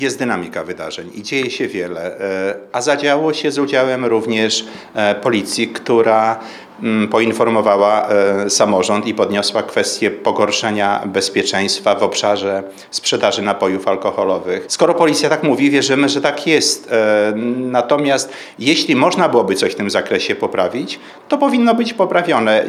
– Stąd analiza przepisów – wyjaśnia Zdzisław Przełomiec, przewodniczący Rady Miasta Suwałki.
Zdzisław-Przełomiec-przewodniczący-Rady-Miasta-Suwałki-1.mp3